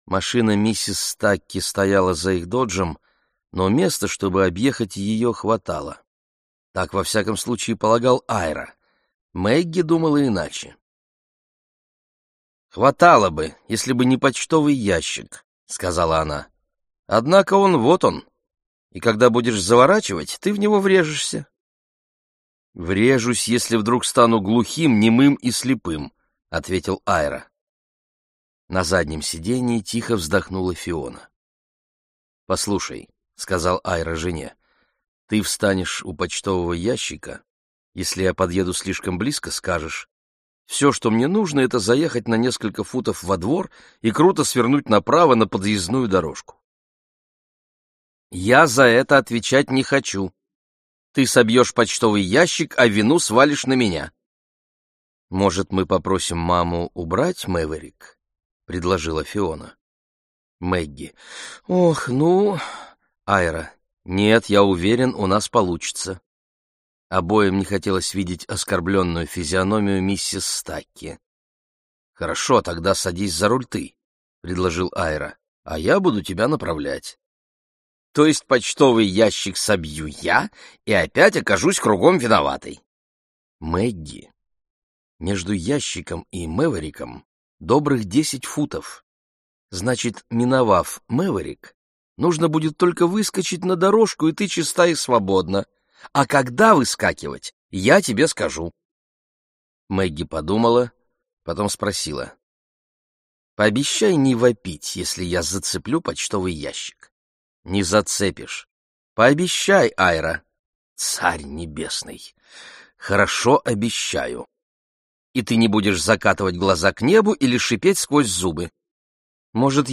Аудиокнига Уроки дыхания | Библиотека аудиокниг